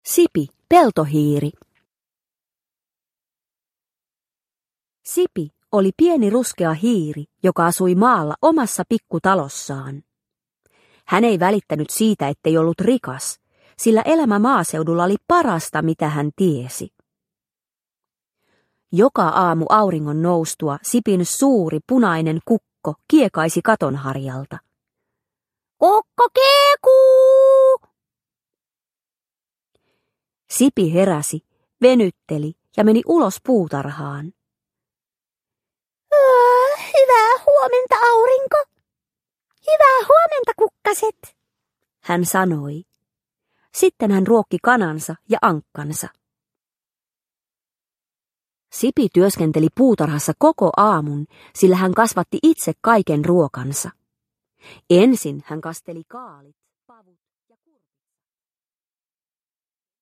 Sipi Peltohiiri – Ljudbok – Laddas ner